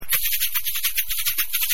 バテリア＆サンバ楽器
ganza.mp3